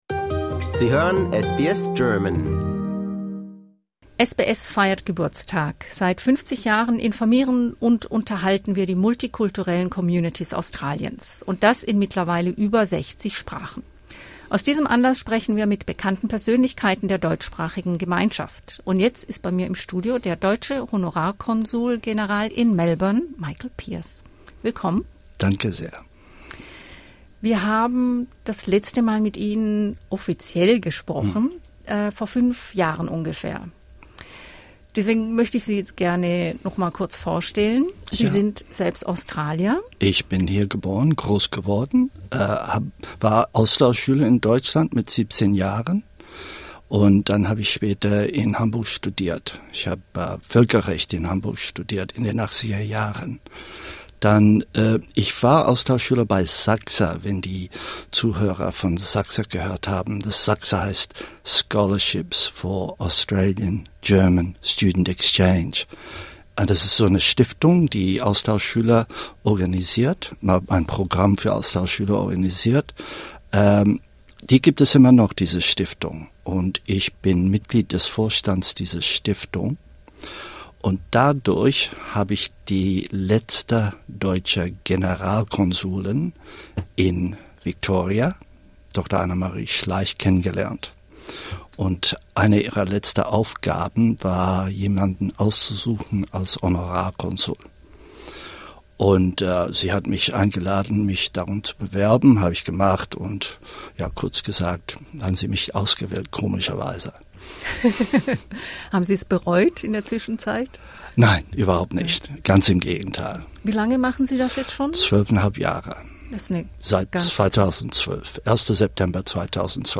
SBS50: Interview mit Melbourne Honorarkonsul Michael Pearce